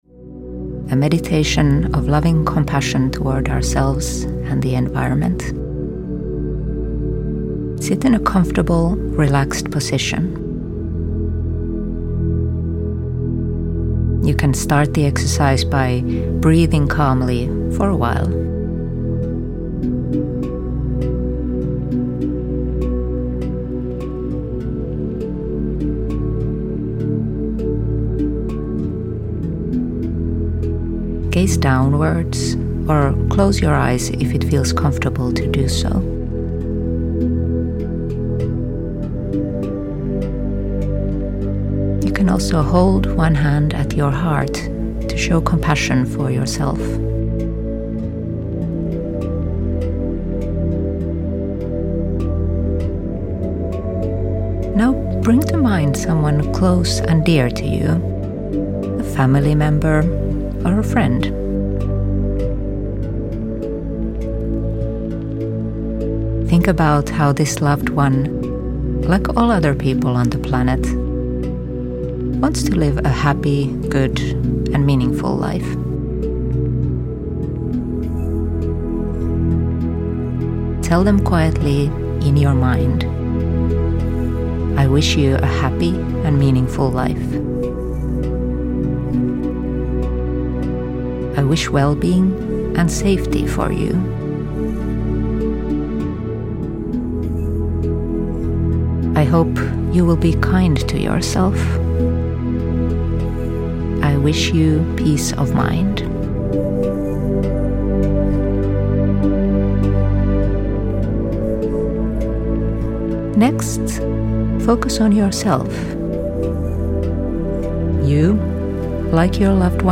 Meditation in english